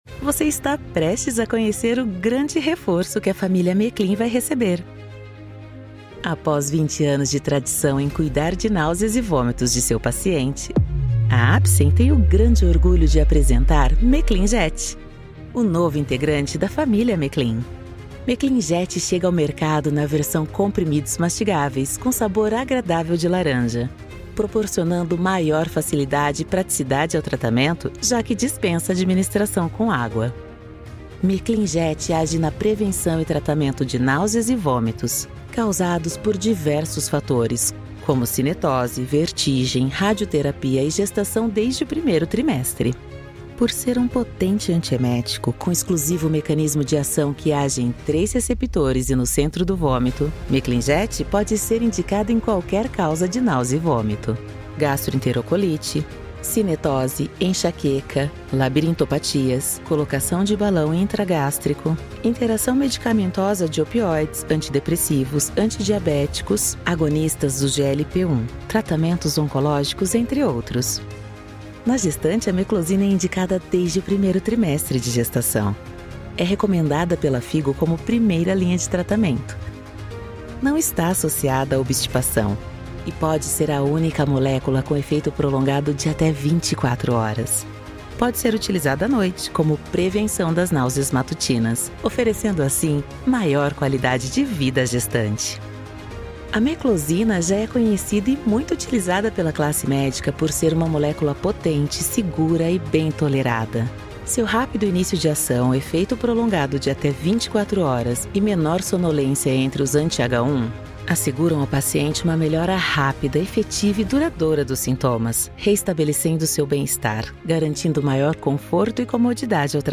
Narração Médica
Trabalho em estúdio próprio totalmente equipado e entrego áudio de alta qualidade, com flexibilidade para atender necessidades exclusivas da sua marca, negócio ou projeto. Minha voz é versátil, polida e neutra, com interpretações personalizadas para seus objetivos.
Contralto